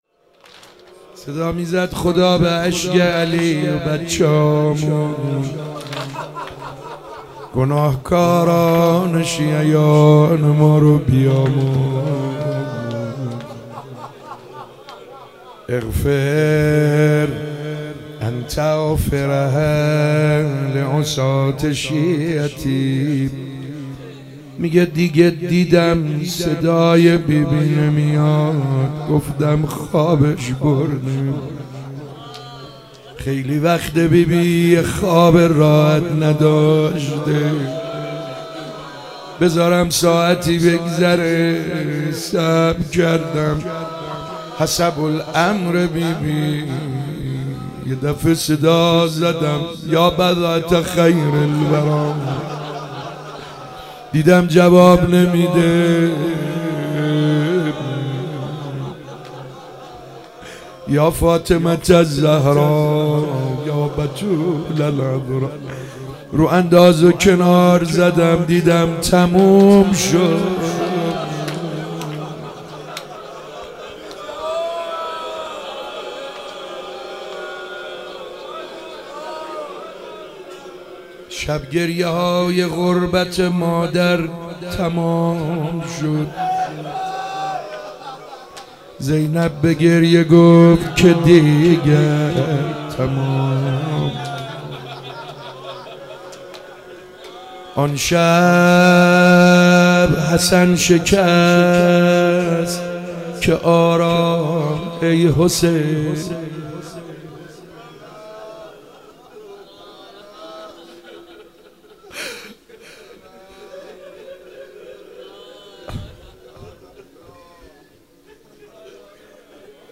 فاطمیه 95 سعید حدادیان واحد مداحی